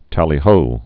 (tălē-hō)